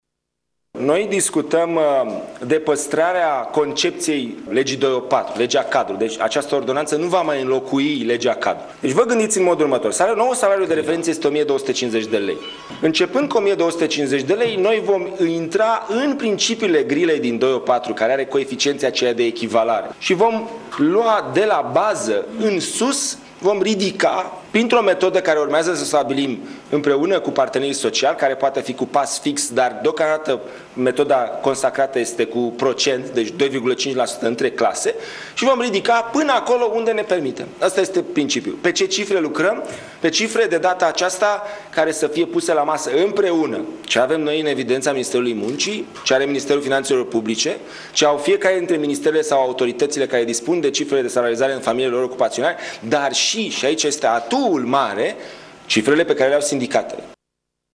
Ministrul Muncii, Dragoș Pâslaru, a explicat procentele de creștere a salariilor și resursele bugetare: